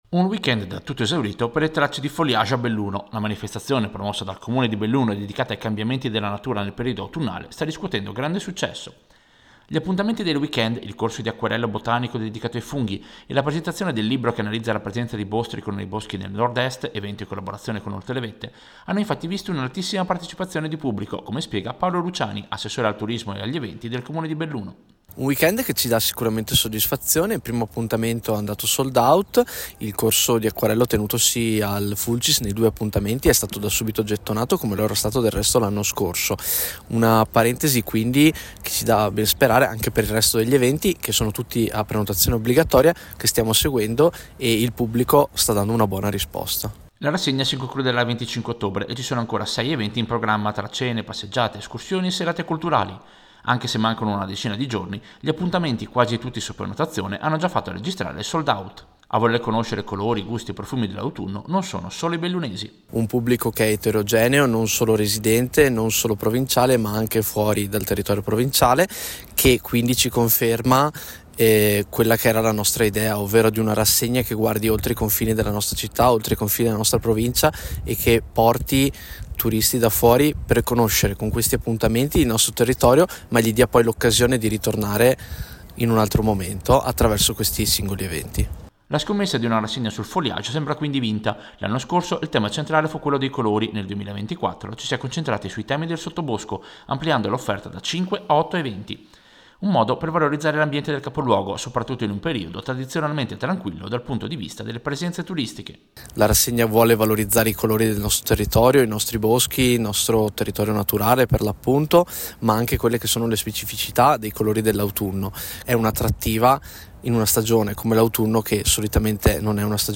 Servizio-Bilancio-foliage-Belluno.mp3